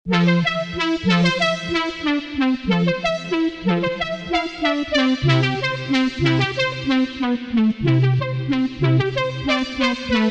FUTURE BASS/EDM SERUM PRESETS
‘Wow’ Lead